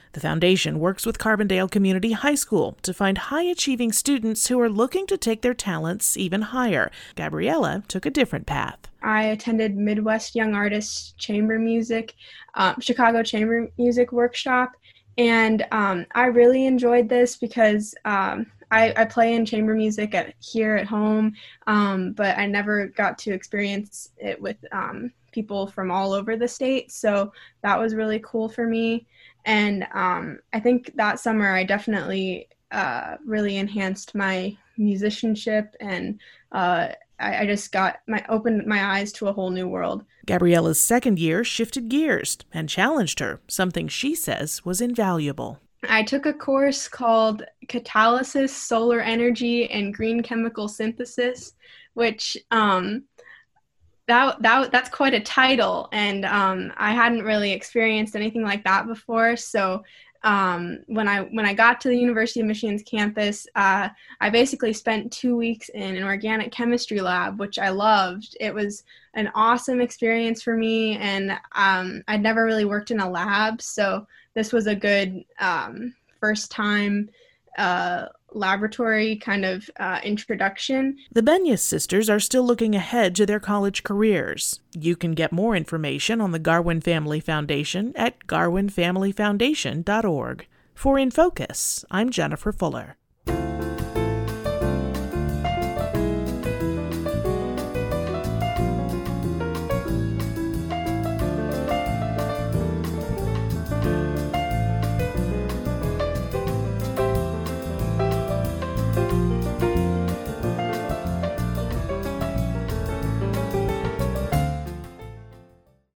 WSIU Radio "In Focus" interviews